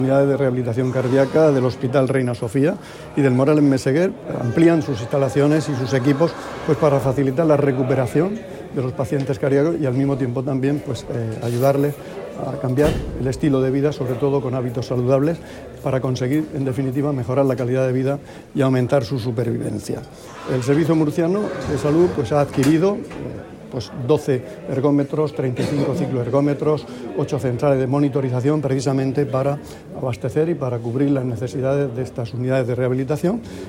Declaraciones del consejero de Salud, Juan José Pedreño, en su visita a la Unidad de Rehabilitación Cardiaca del hospital Reina Sofía.